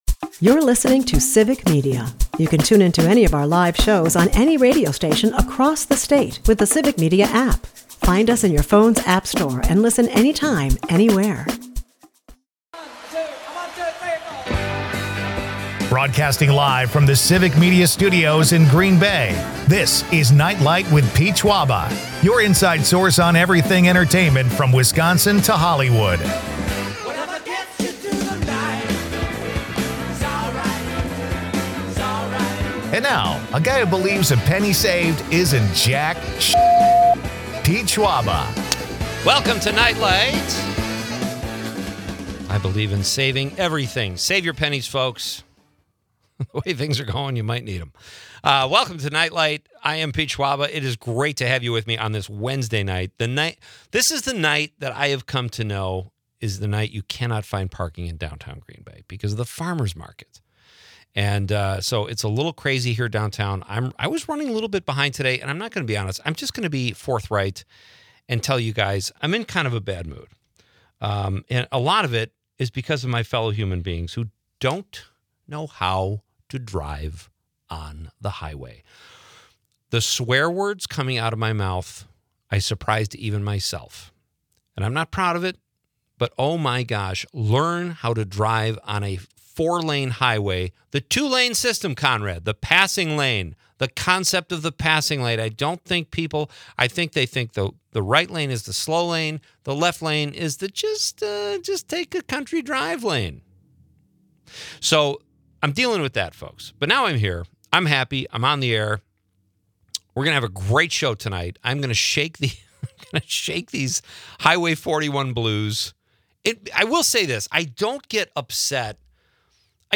Leave the stress of the day behind with entertainment news, comedy and quirky Wisconsin.